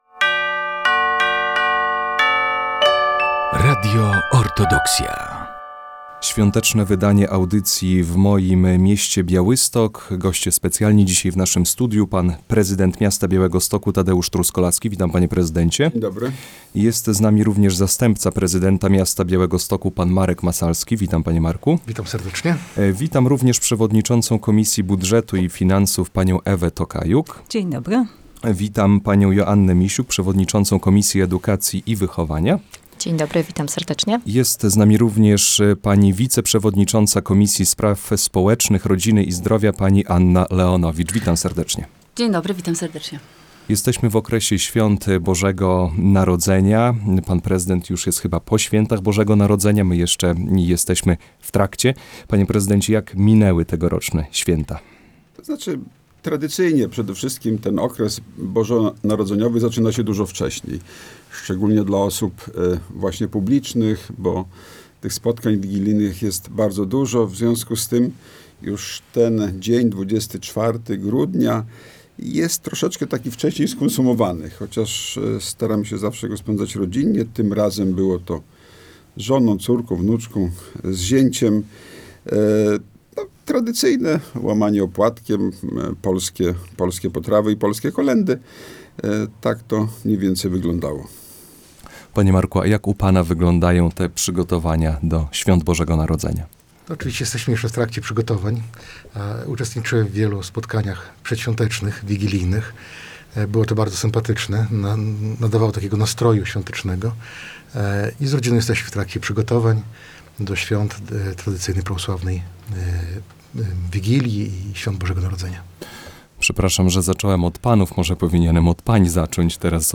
W moim mieście Białystok - świąteczna audycja